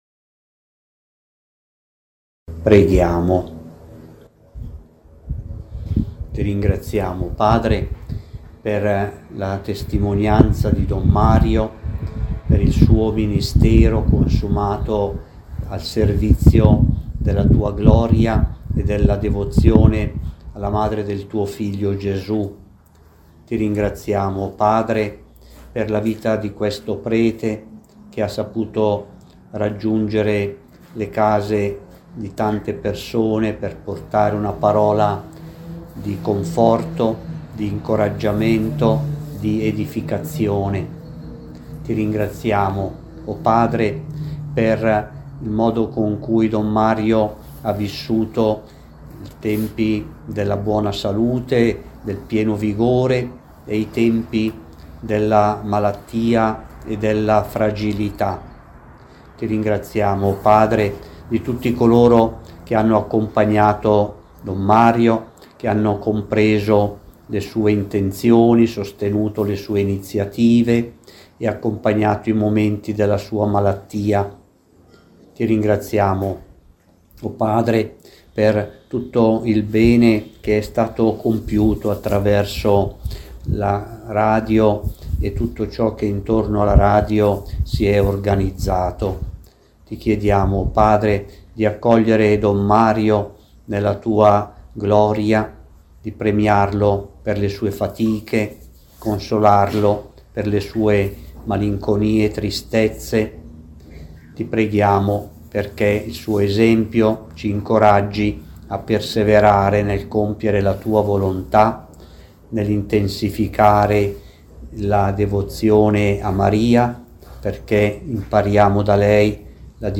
Mons. Delpini ha pregato un mistero del Santo Rosario e prima della Benedizione ha pregato e ringraziato il Signore: